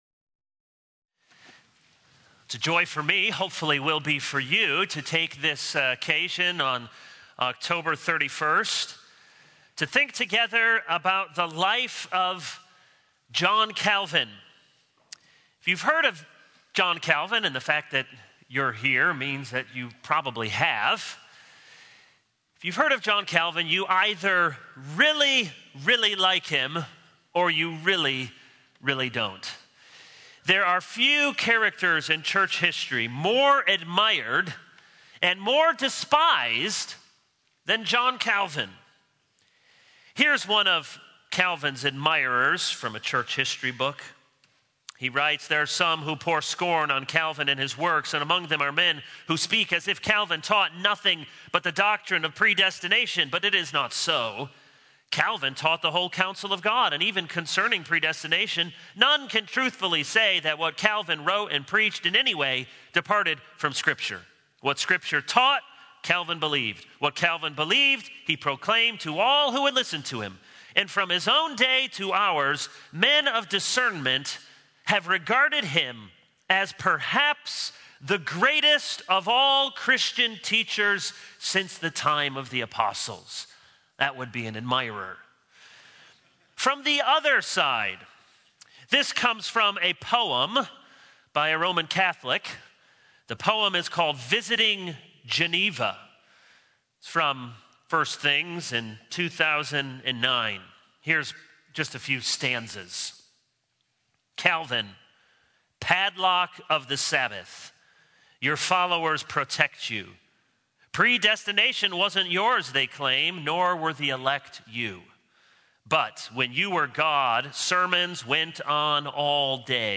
All Sermons Boasting in the Lord 0:00 / Download Copied!